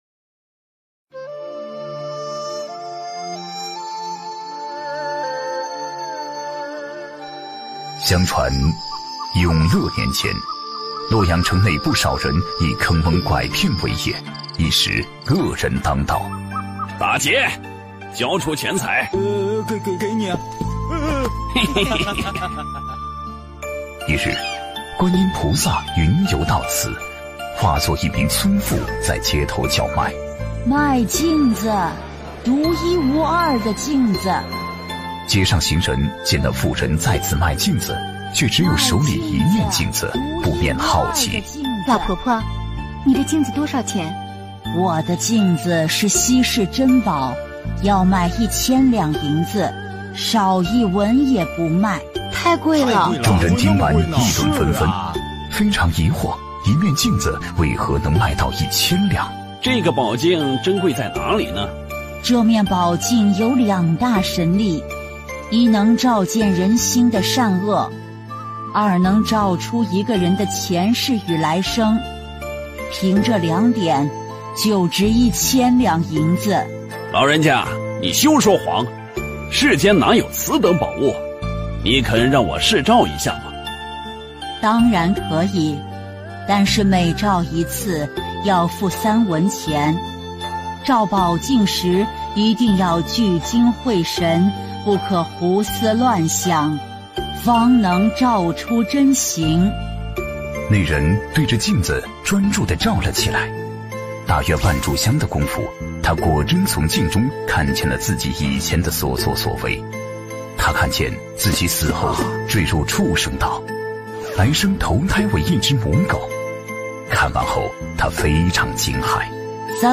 ▶ 语 音 朗 读